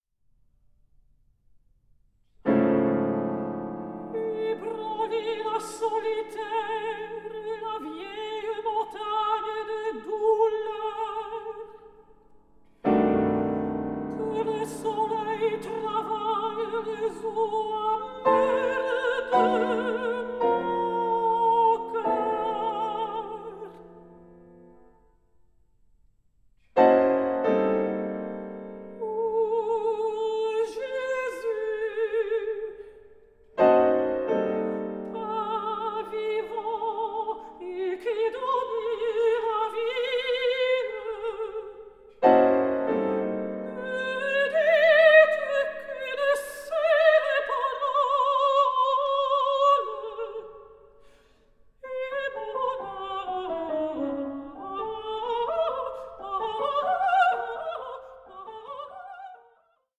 mezzo-soprano
pianist